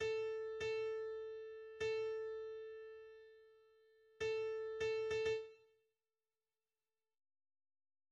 A lilypond a zongora hangszínét használja alaphelyzetben, de ez is változtatható.